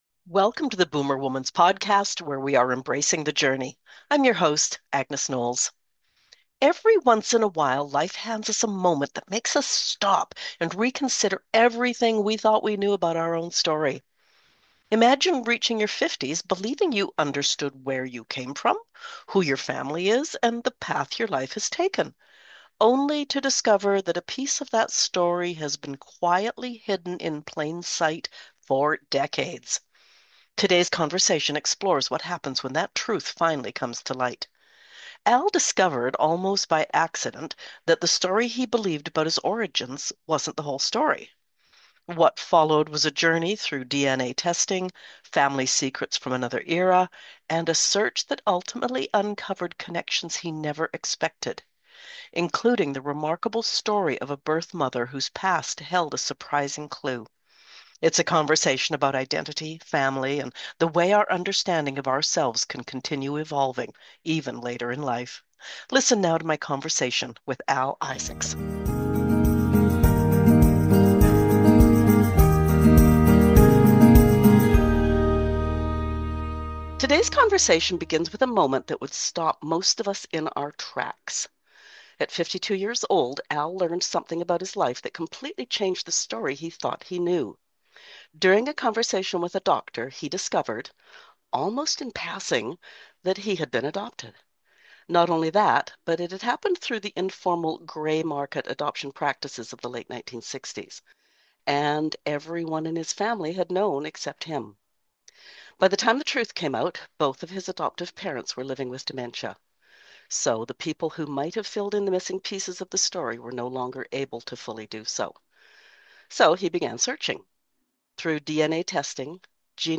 In this conversation we explore: